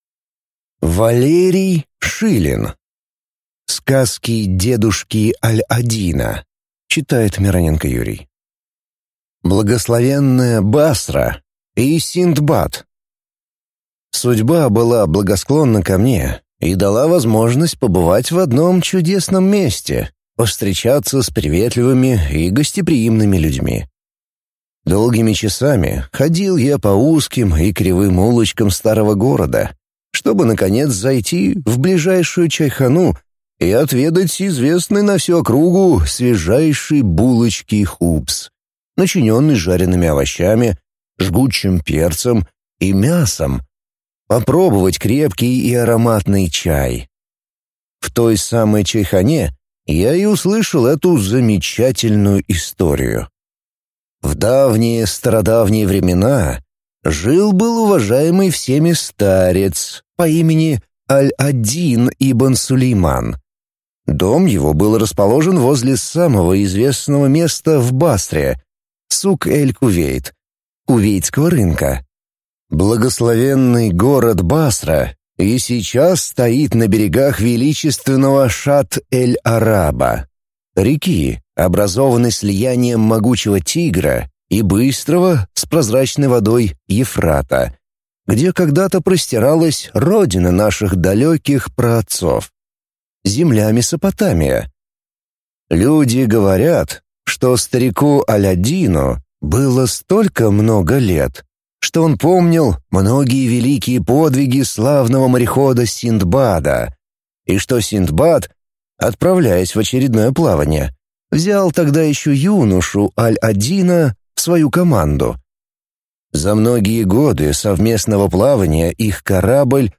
Аудиокнига Сказки дедушки Аль-Аддина | Библиотека аудиокниг